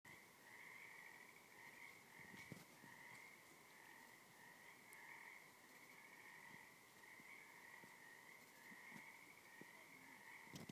Sure sign of spring? Forest frogs!